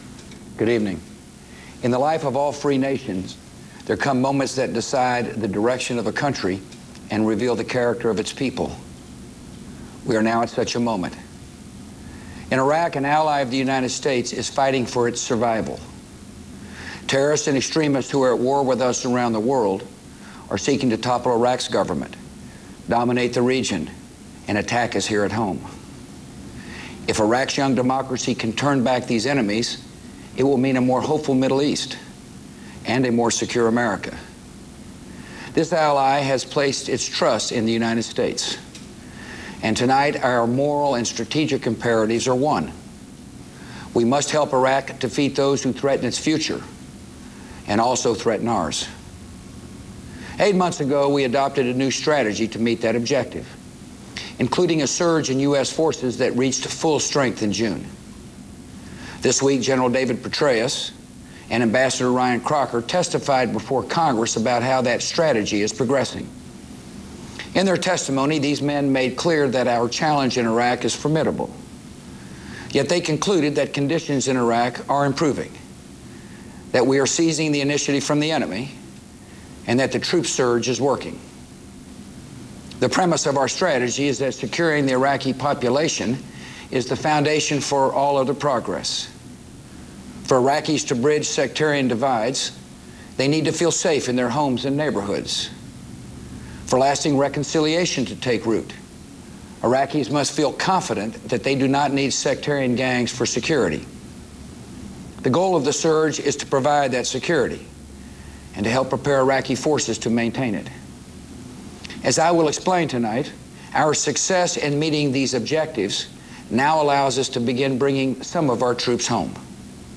President George W. Bush gives a prime-time address presenting his strategy for gradually moving U.S. troops out of Iraq.
Broadcast on CNN, Sept. 13, 2007.